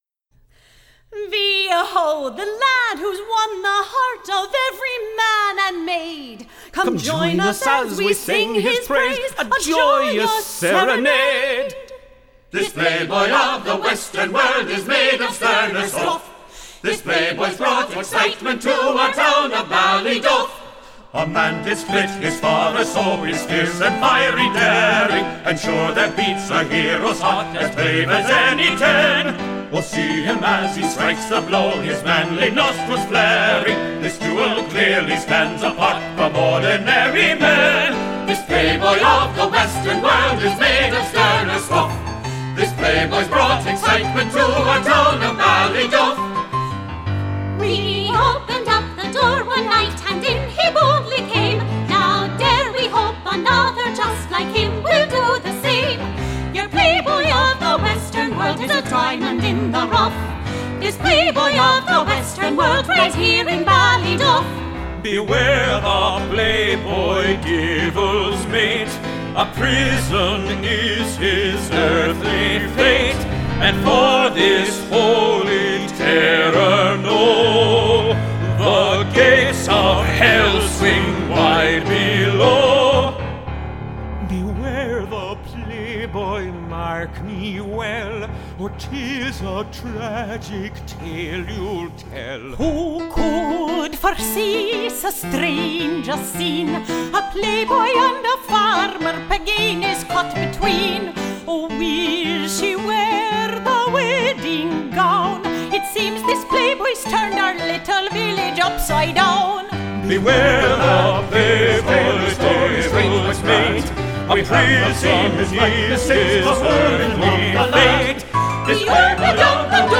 - An Irish Musical Comedy
(Cast)